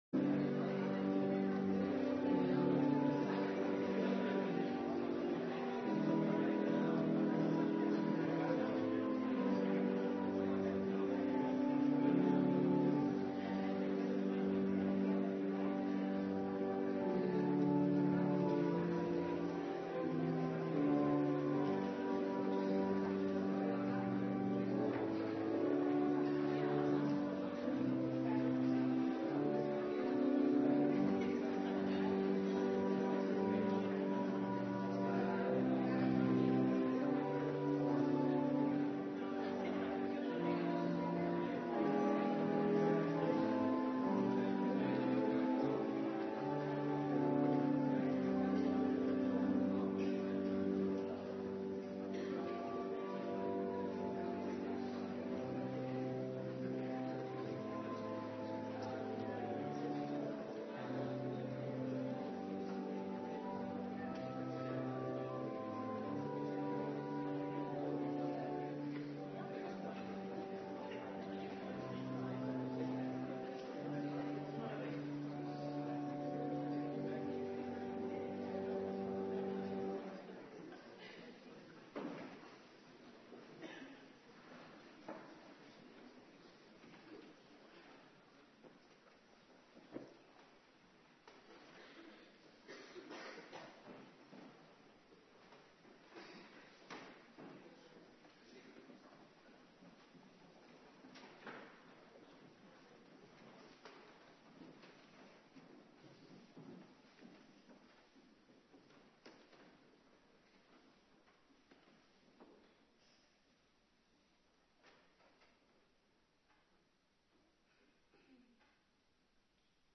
Preken (tekstversie) - Geschriften - HC zondag 49 | Hervormd Waarder